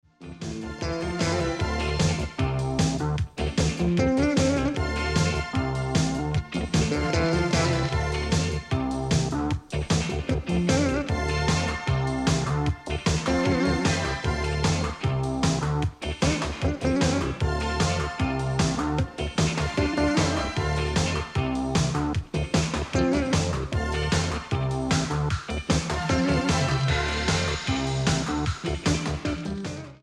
Fab extended edits and rare remixes.